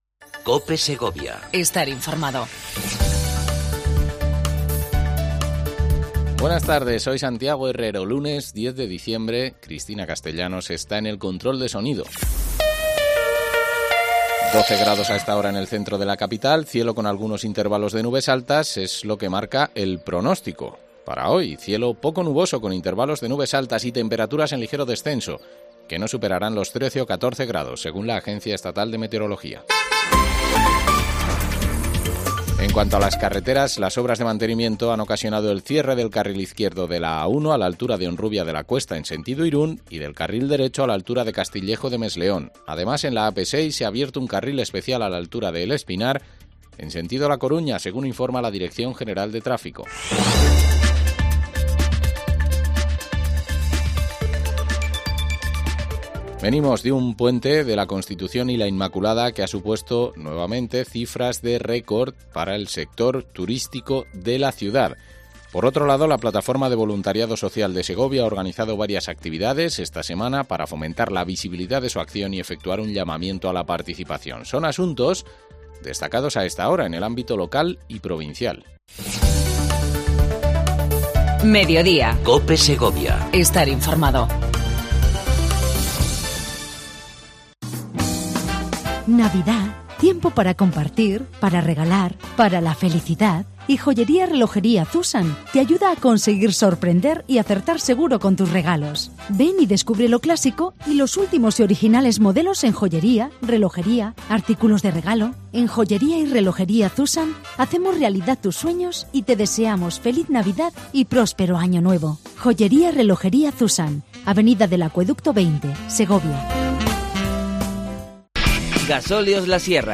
Entrevista mensual a Clara Luquero, Alcaldesa de la capital segoviana con la que hablamos de la actualidad de la provincia.